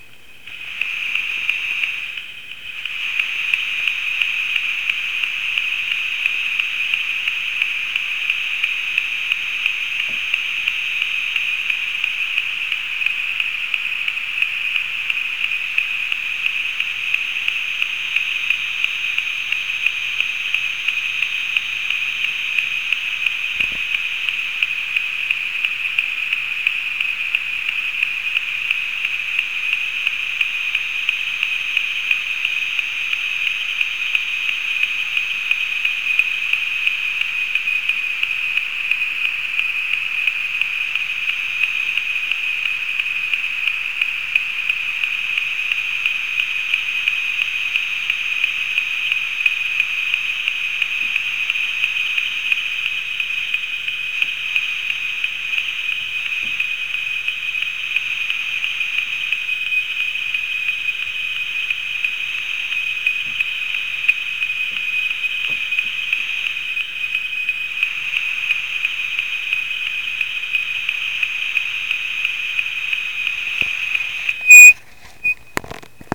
Â The reason this signal appeared in the recording is because it is an external T coil receiver recording.
This is the ambient recording of a signal.
ambient frequency recording T-coil and frequency counter
Â It pulses at about 3 Hz.
ambient-signal-recording.mp3